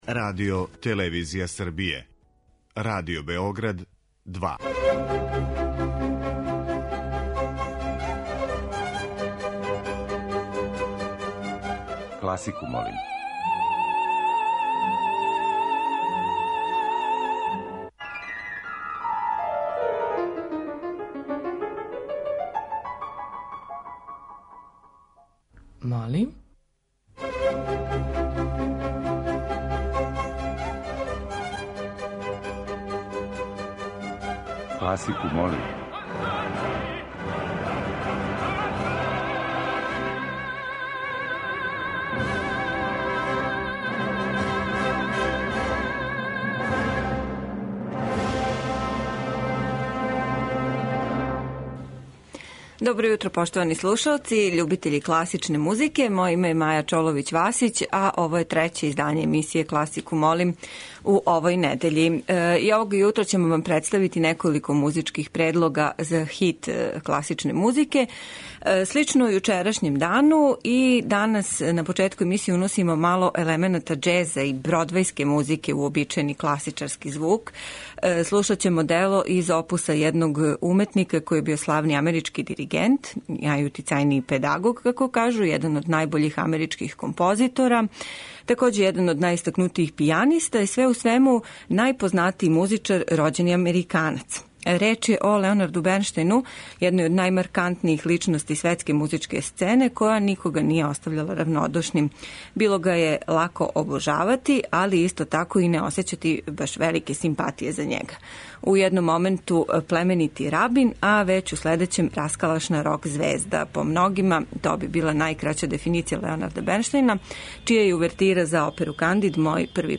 Избор за недељну топ-листу класичне музике Радио Београда 2